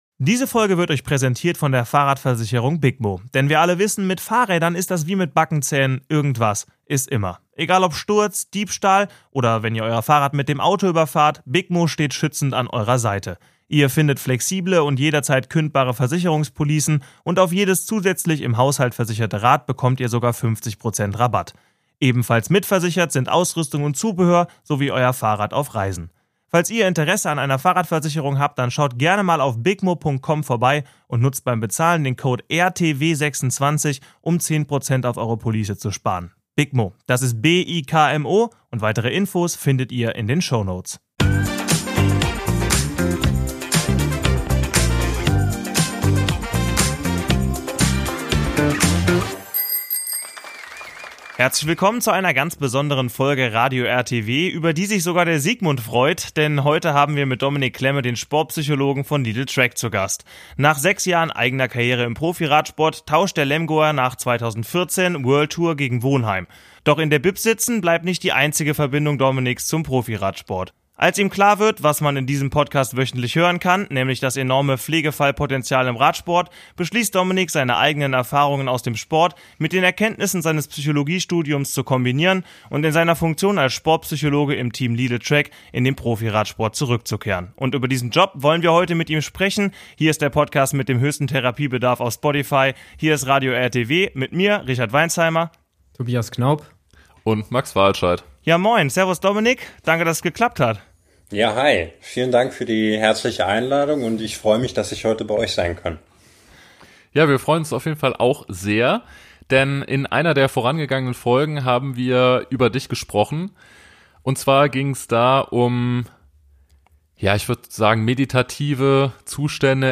Endlich mal wieder eine Folge Radio RTW mit Gast.